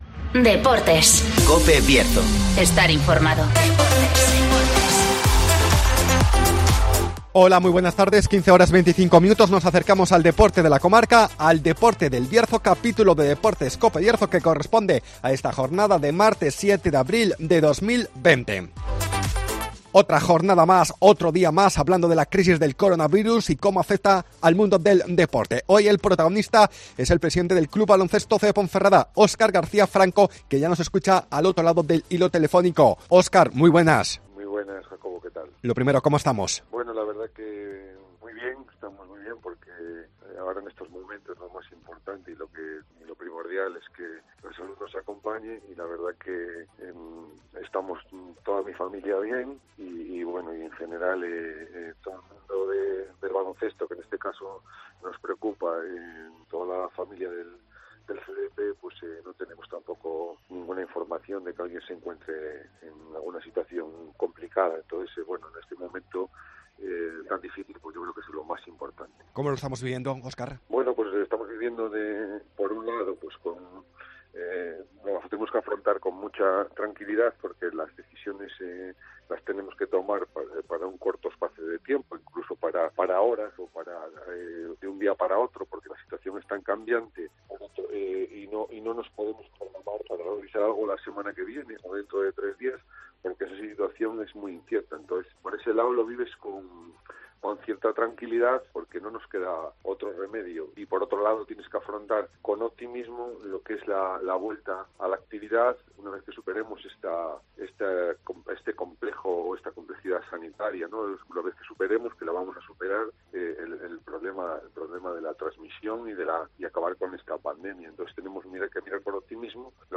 -Entrevista